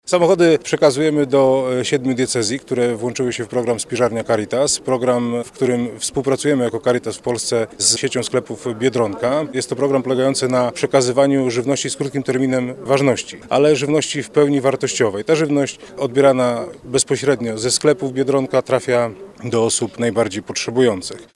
Mówi ksiądz